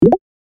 select-expand.ogg